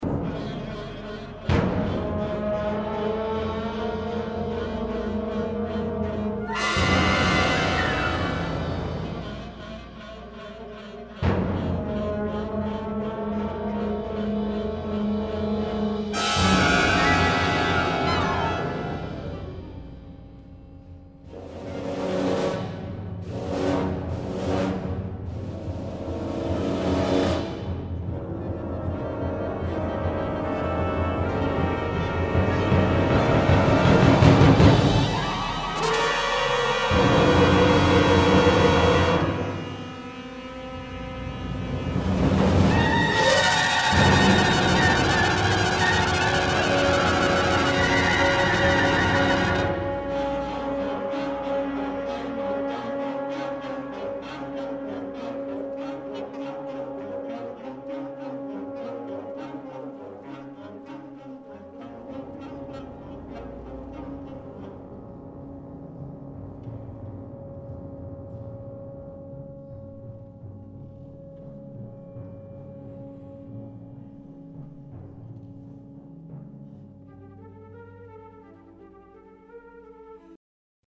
Poem for Symphonic Wind Band
and more ominous mood during a ferocious storm."